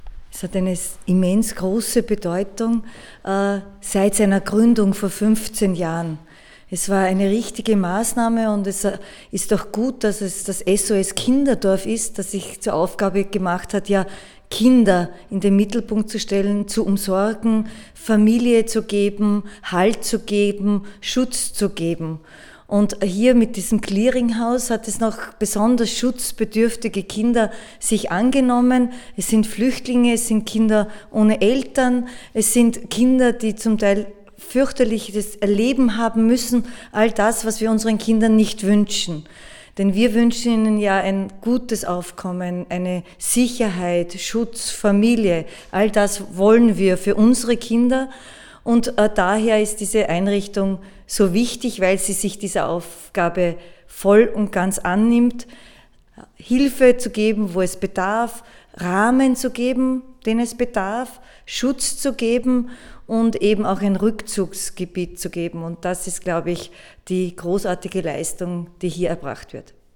O-Ton Pallauf zum 15-Jahr-Jubiläum SOS-Kinderdorf Clearinghouse